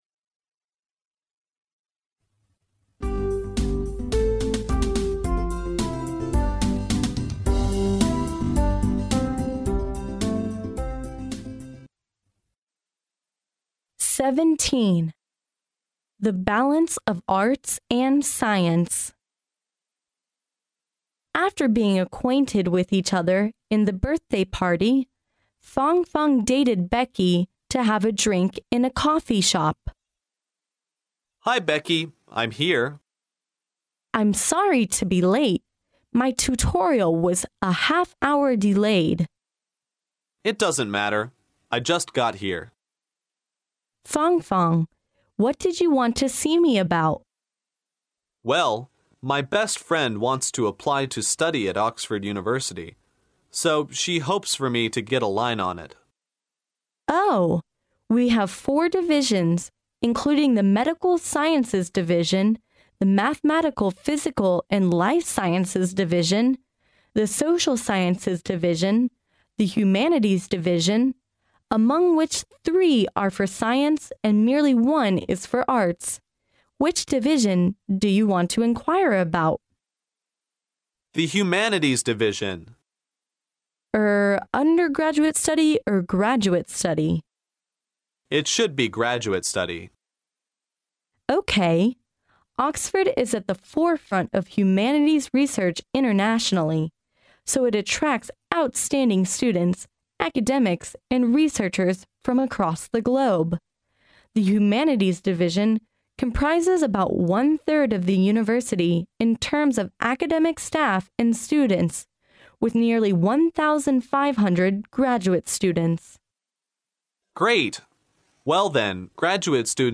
牛津大学校园英语情景对话17：兼容并蓄，文理平衡（mp3+中英）